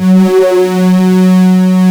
OSCAR 10 F#3.wav